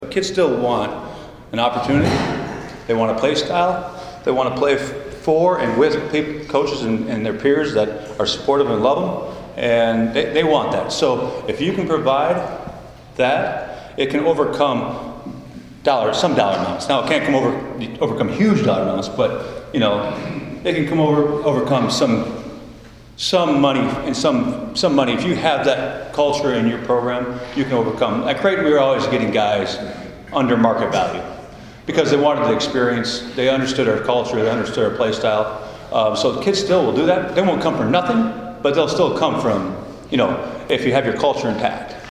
talks basketball to Hopkinsville Kiwanis Club